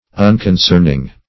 Search Result for " unconcerning" : The Collaborative International Dictionary of English v.0.48: Unconcerning \Un`con*cern"ing\, a. Not interesting or affecting; insignificant; not belonging to one.